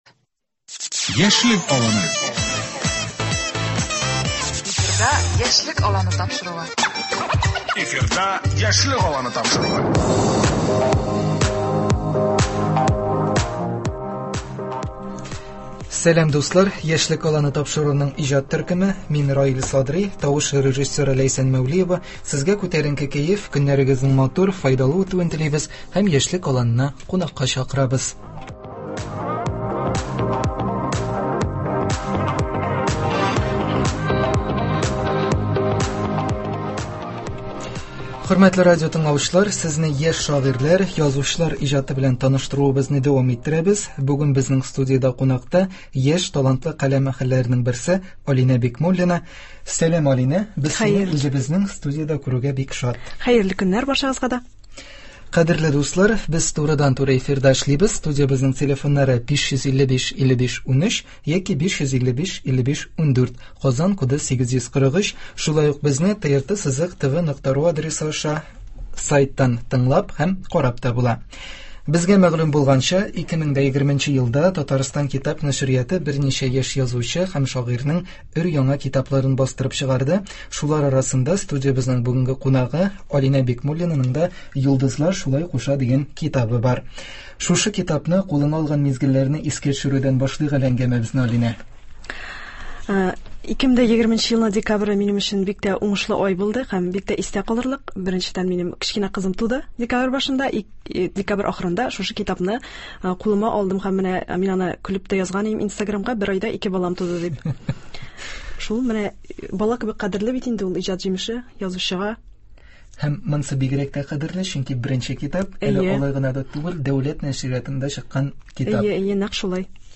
Сезне яшь шагыйрьләр, язучылар иҗаты белән таныштурыбызны дәвам иттерәбез. Бүген безнең студиядә кунакта